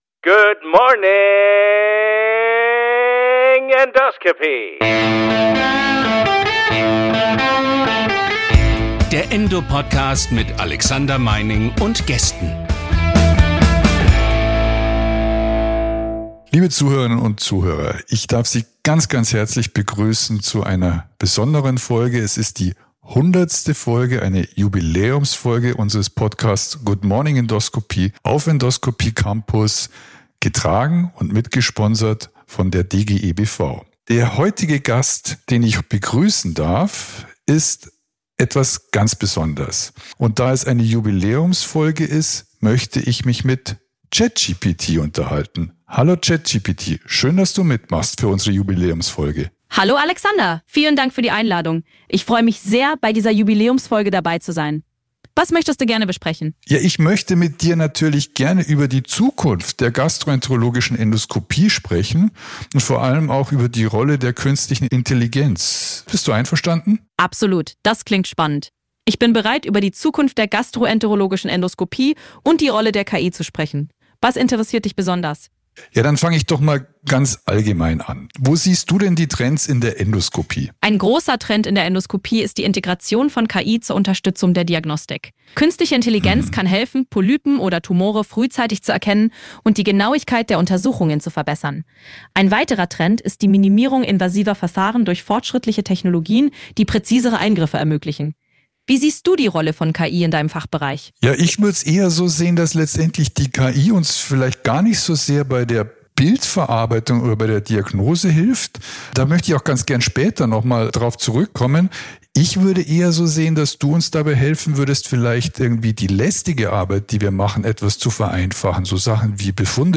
Meine Gesprächspartnerin ist dieses Mal das geballte Wissen des Internets, vertreten durch ChatGPT 5. Wie sieht die künstliche Intelligenz ihre Rolle als künstliche Intelligenz in der Endoskopie?